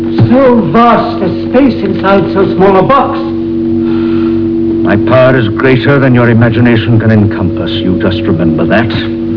amazed at the Master's power.